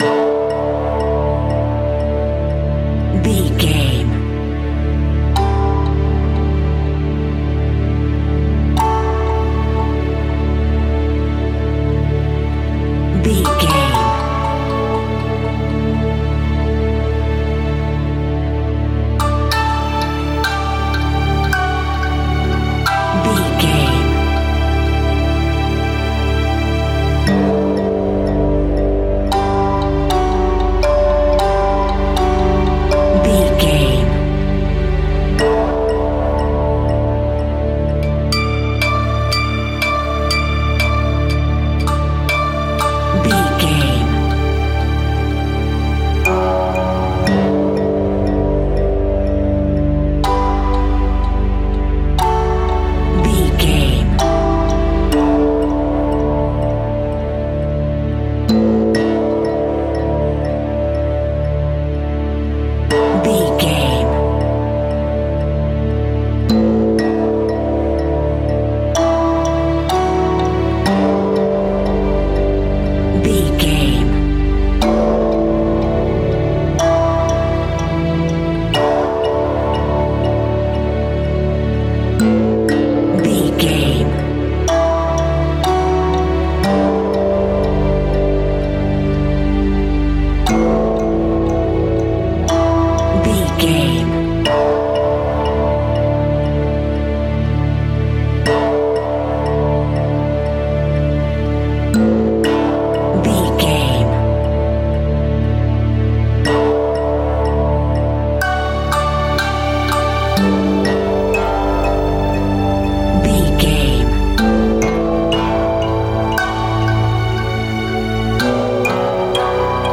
Aeolian/Minor
Slow
strings
gongs
taiko drums